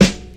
• Classic Studio Hip-Hop Snare One Shot F# Key 97.wav
Royality free snare single hit tuned to the F# note. Loudest frequency: 1203Hz
classic-studio-hip-hop-snare-one-shot-f-sharp-key-97-3IH.wav